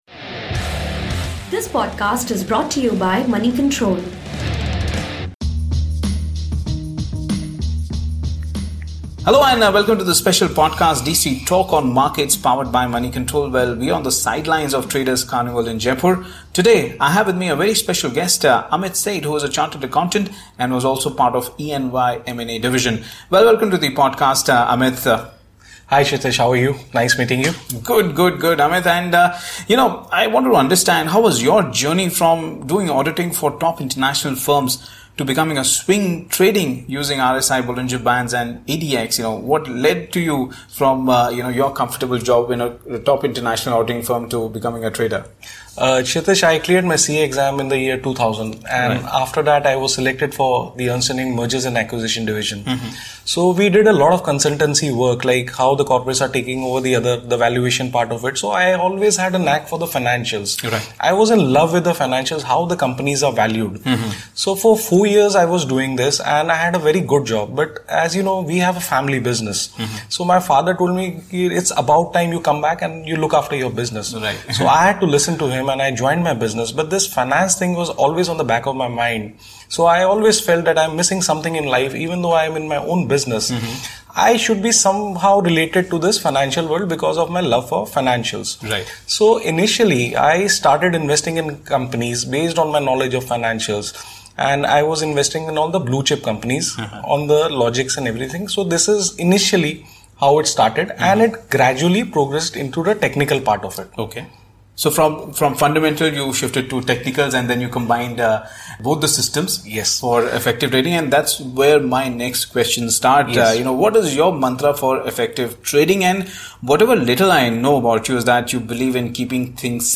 on the sidelines of Traders Carnival